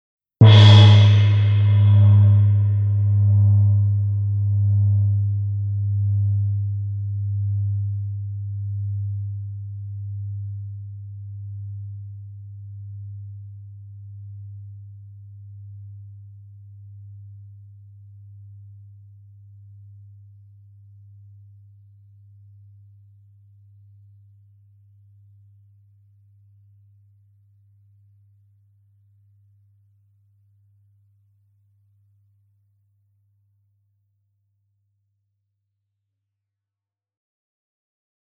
PLAYTECHの24インチゴング。